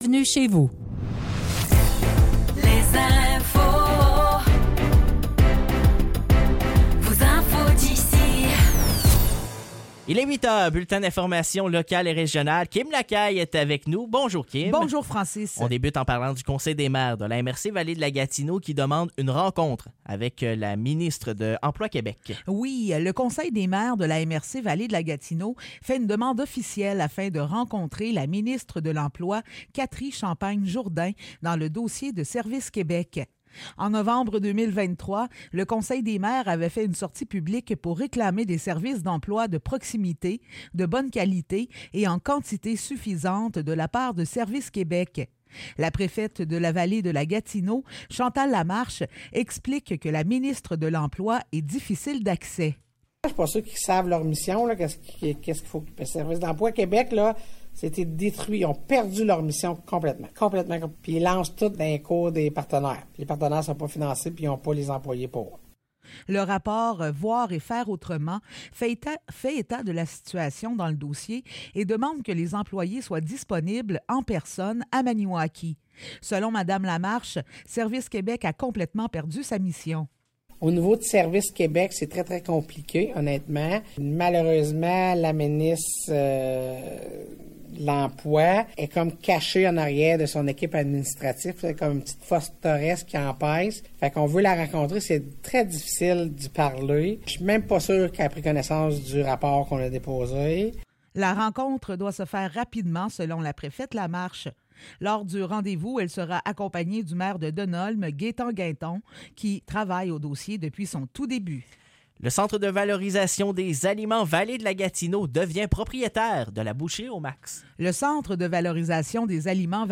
Nouvelles locales - 12 janvier 2024 - 8 h